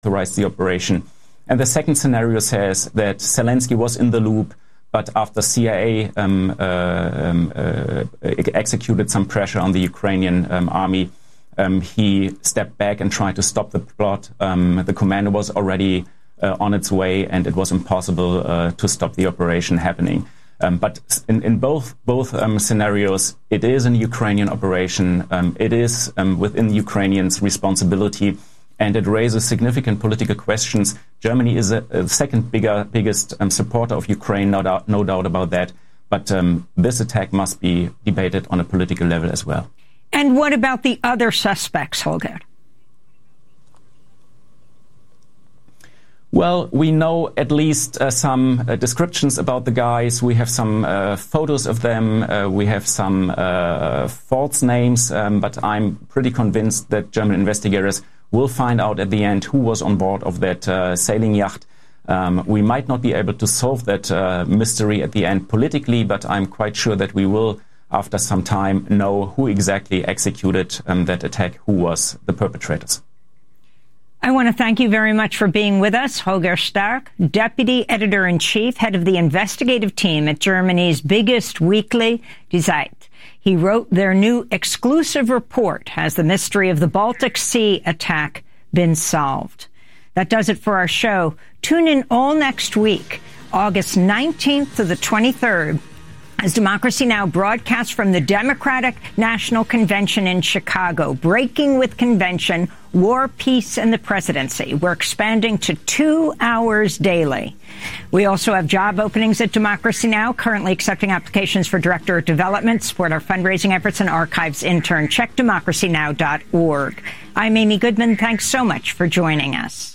The Gap is talk radio aimed at bridging the generational divide.